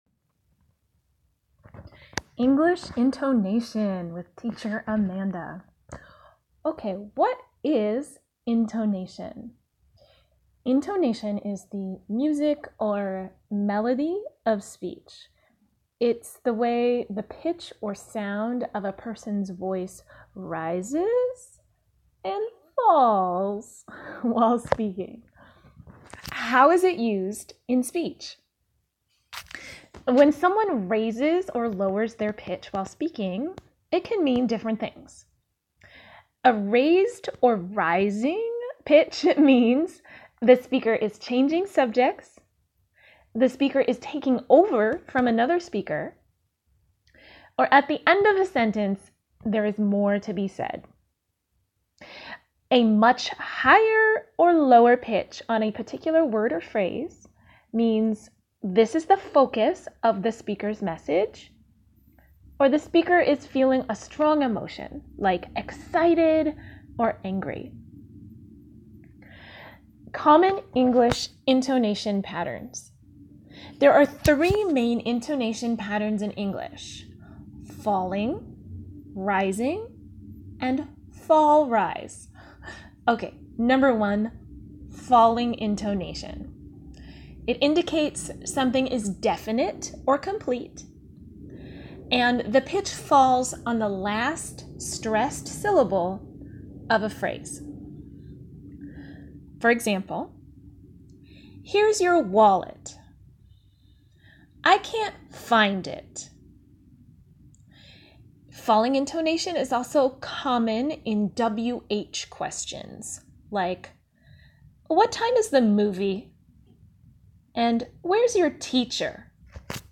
There are three main intonation patterns in English: falling, rising, and fall-rise.